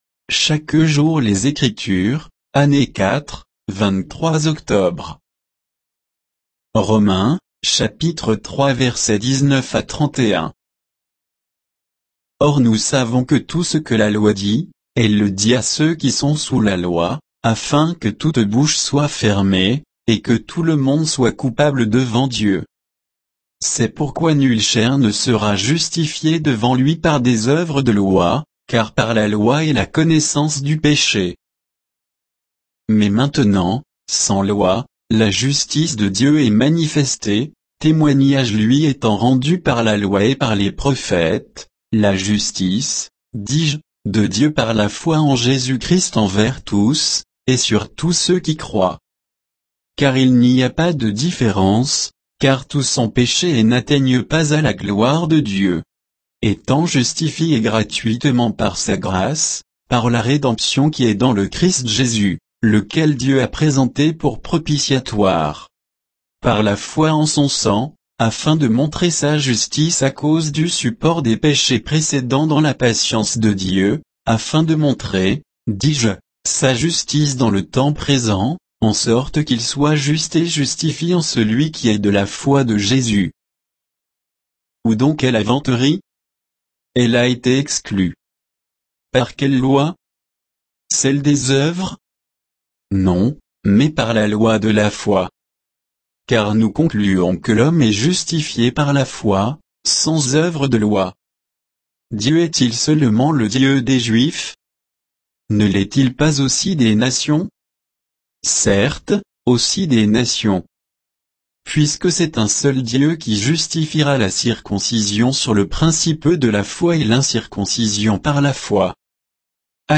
Méditation quoditienne de Chaque jour les Écritures sur Romains 3